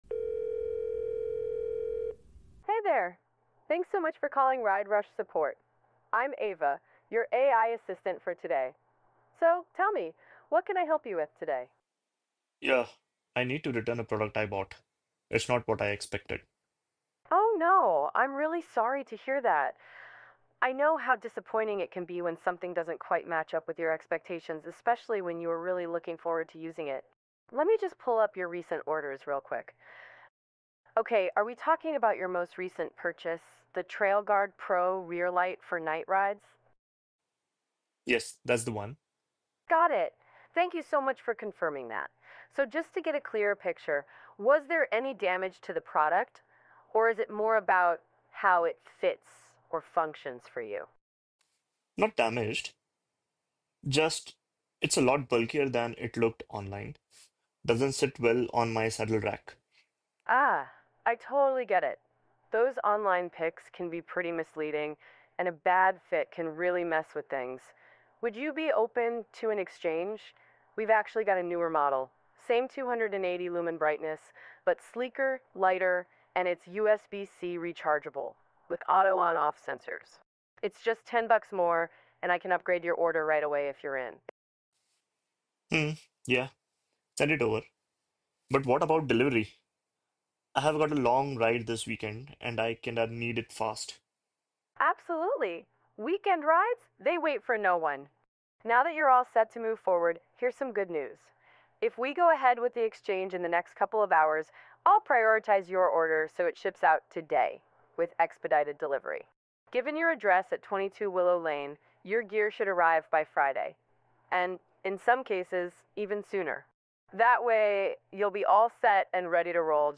Customer-AI-voice-agent.mp3